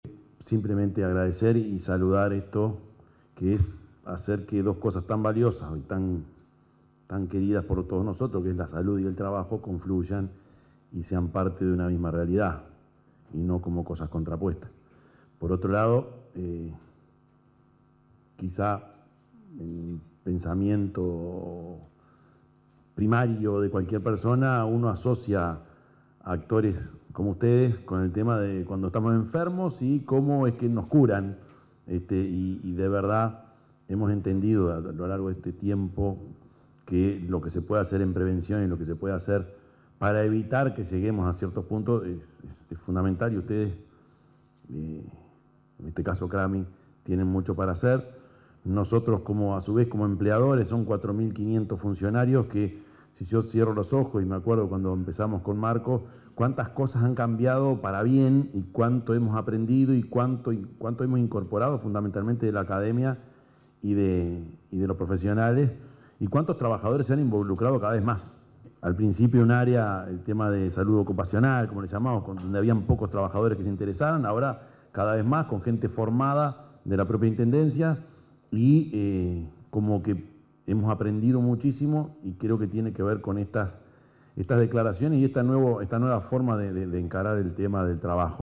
Intendente Orsi participó de una actividad por el Día Mundial de la Seguridad y Salud en el Trabajo organizada por CRAMI
El Intendente de Canelones, Prof. Yamandú Orsi, agradeció la realización de esta clase de eventos y saludó a los presentes.
yamandu_orsi_-_intendente_de_canelones_11.mp3